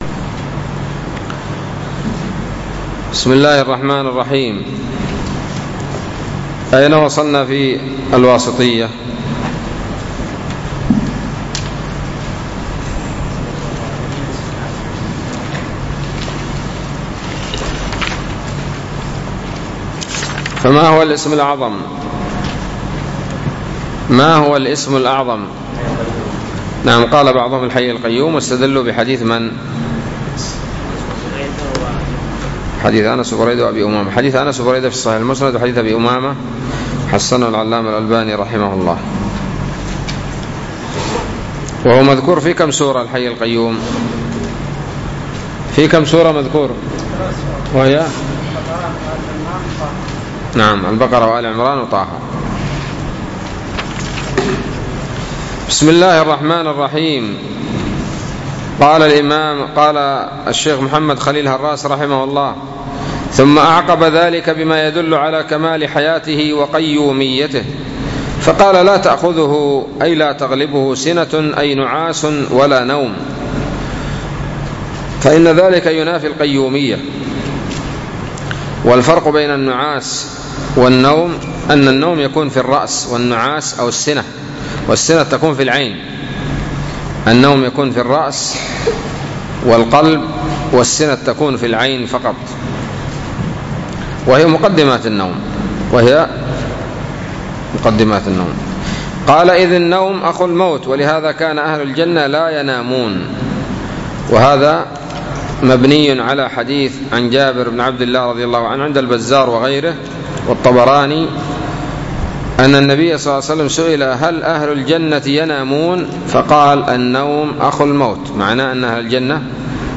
الدرس السابع والثلاثون من شرح العقيدة الواسطية